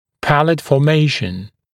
[‘pælət fɔː’meɪʃn][‘пэлэт фо:’мэйшн]формирование нёба